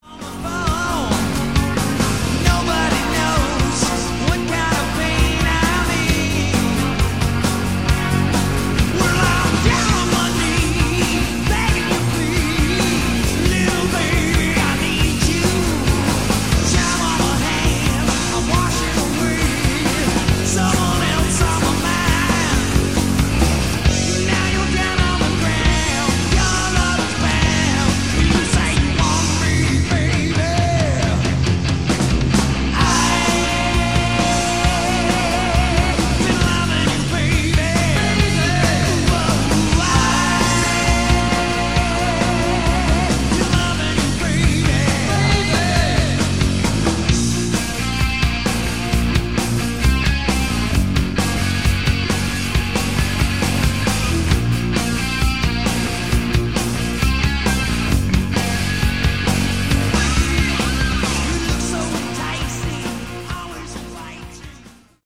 Category: Hard Rock
lead guitar, backing vocals
lead vocals
bass
drums